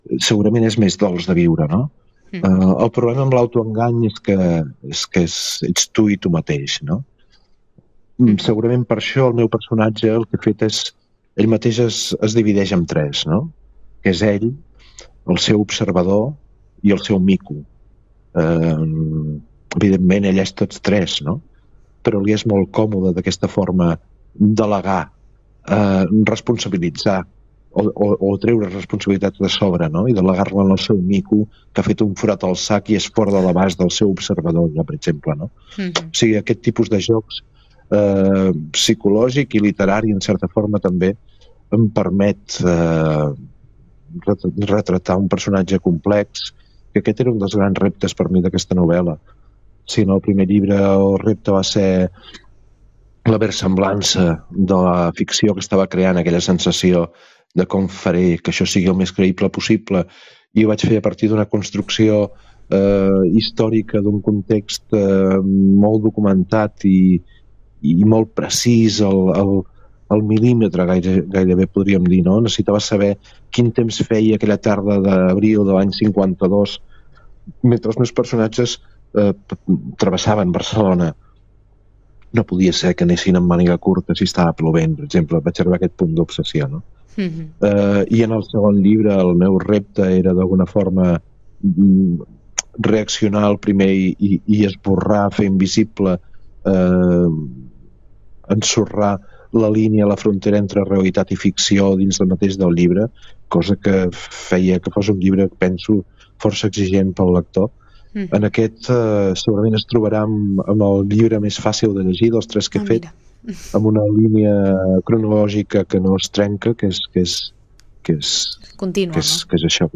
Supermatí - entrevistes